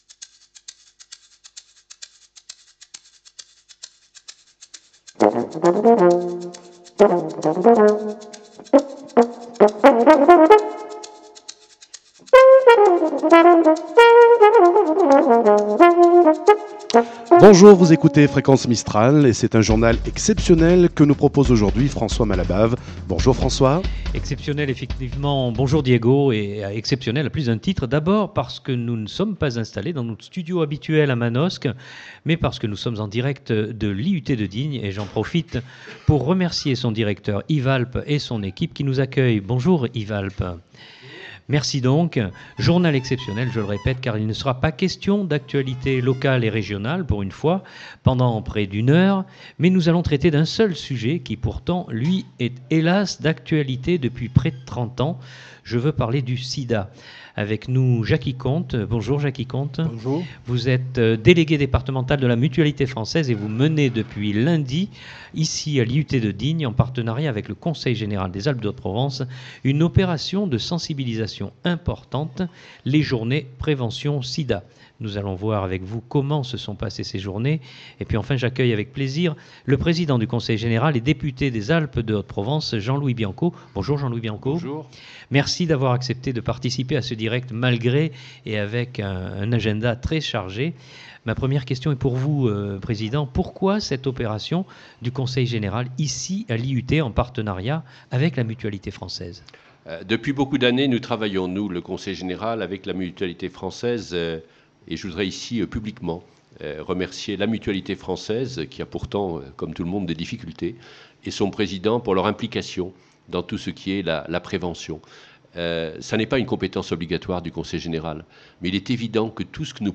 Journée prévention SIDA le 2 décembre 2010 Emission spéciale en direct de l'IUT de Digne
Émission en direct de l'IUT Photos